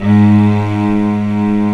55m-orc03-G#1.wav